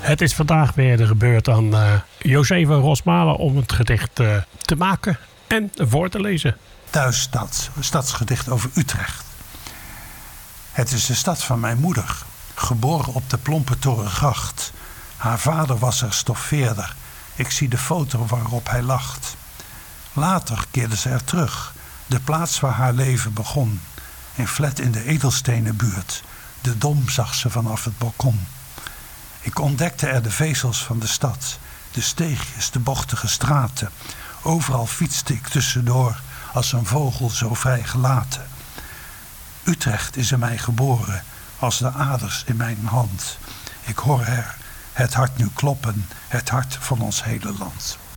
draagt�voor�zijn�gedichtover�Thuisstad,�stadsgedicht�over�Utrecht.